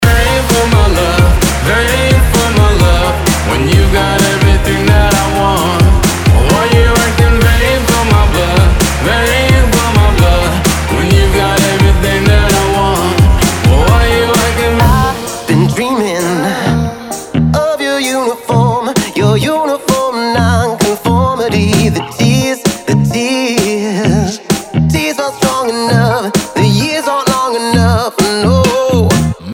track pop-dance yang upbeat, ceria dan mengundang perhatian